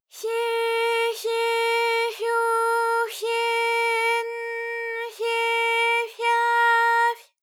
ALYS-DB-001-JPN - First Japanese UTAU vocal library of ALYS.
fye_fye_fyo_fye_n_fye_fya_fy.wav